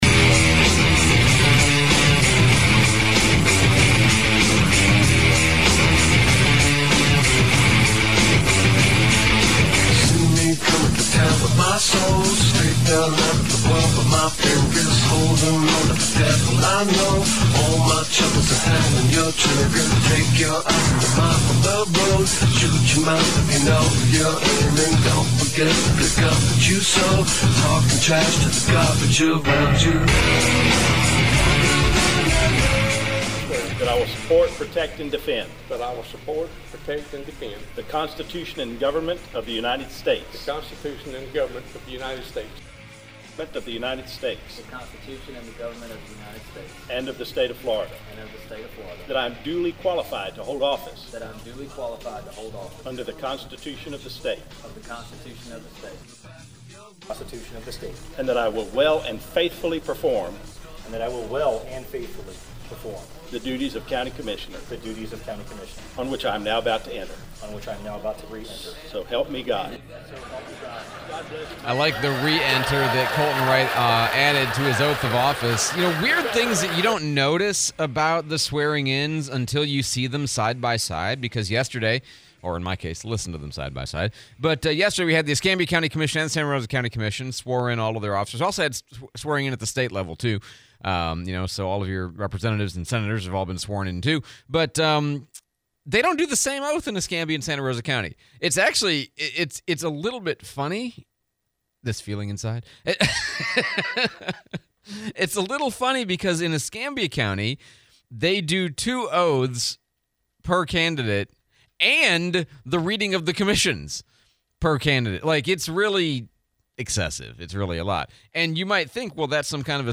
Escambia and Santa Rosa County Commissioners' Oath of Office Ceremony / Interview with Sheriff Chip Simmons (replay)